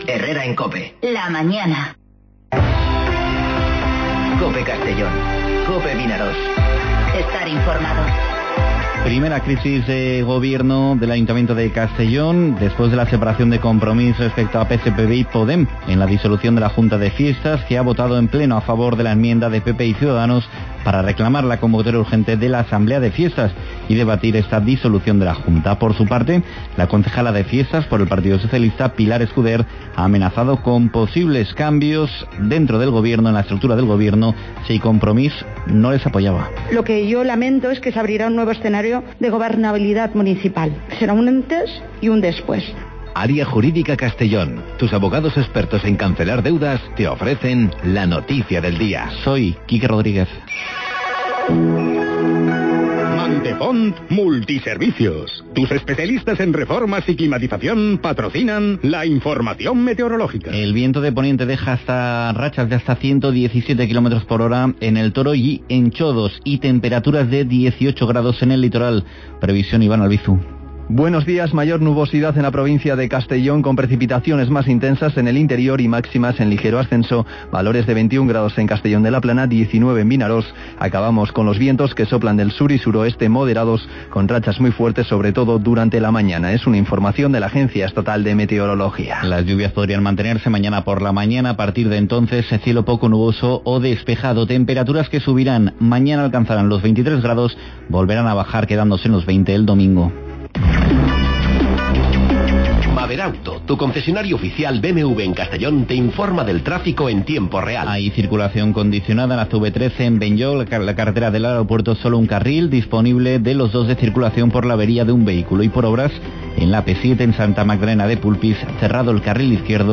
Informativo Herrera en COPE Castellón (20/12/2019)